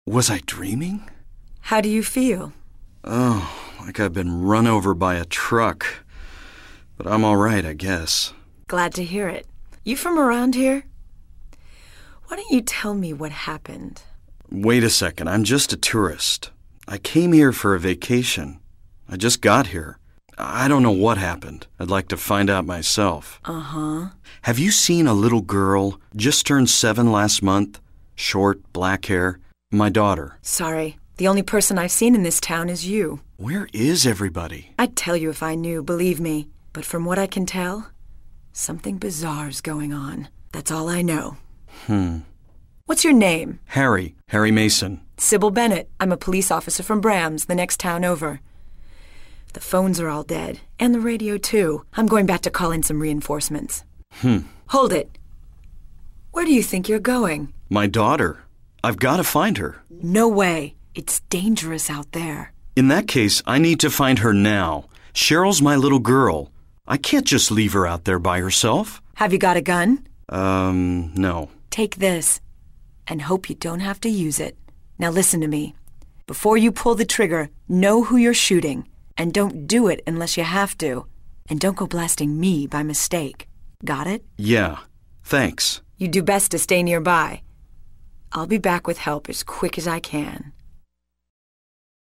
VOICE AND CUTSCENES